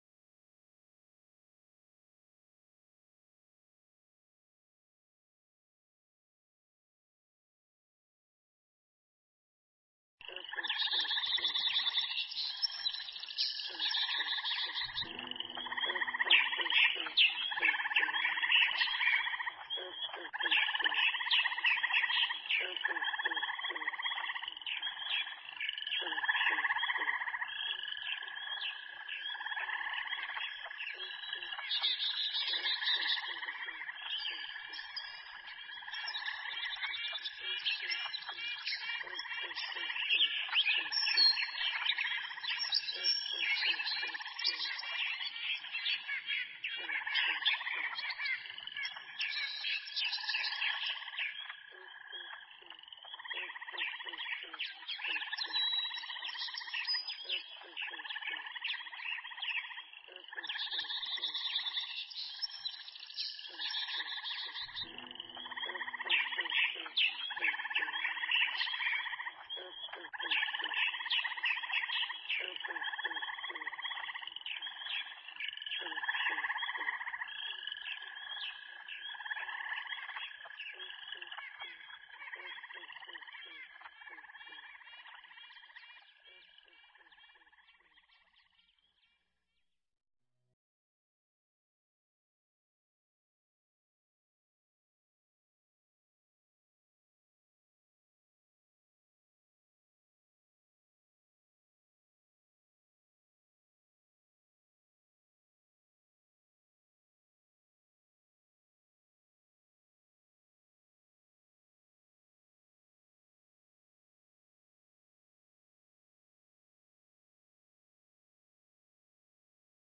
Mp3 Pháp Thoại Tròn Đầy Thái Hư – Hòa Thượng Thích Thanh Từ giảng tại Thiền Viện Trúc Lâm Phụng Hoàng , Đà Lạt, ngày 9 tháng 5 năm 2006